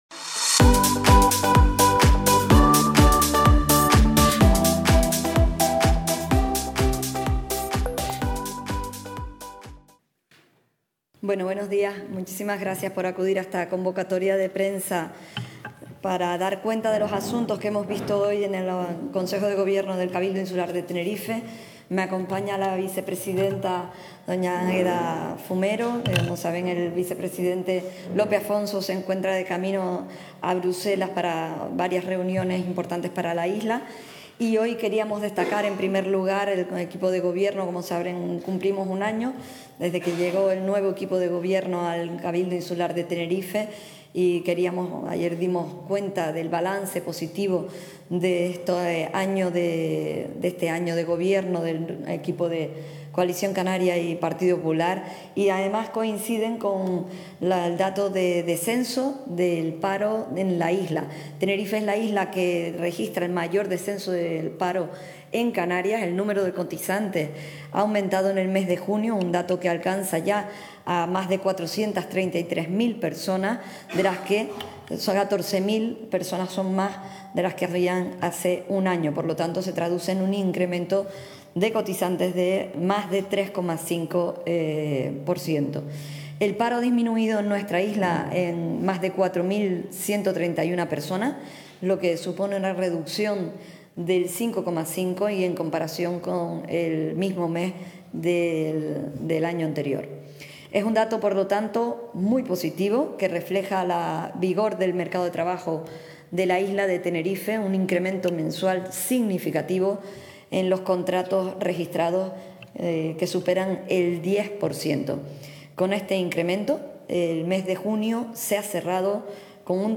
El Cabildo de Tenerife ha aprobado una partida de 10 millones de euros para que los ayuntamientos de la isla desarrollen proyectos que fomenten el empleo en Tenerife. Así lo han expuesto la presidenta del Cabildo, Rosa Dávila, y la vicepresidenta...